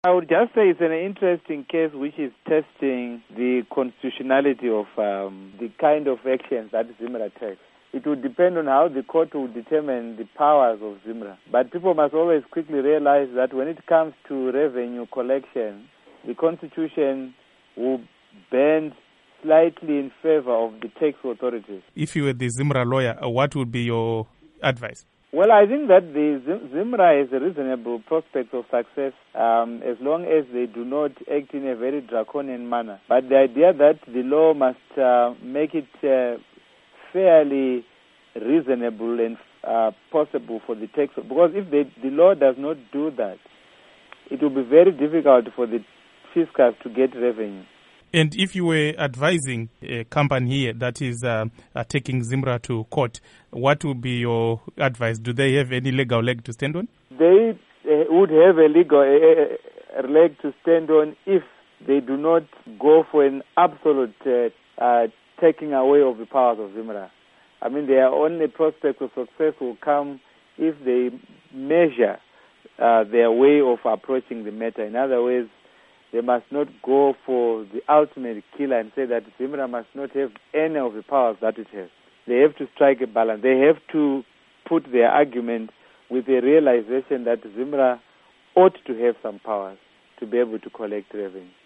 Interview With Professor Lovemore Madhuku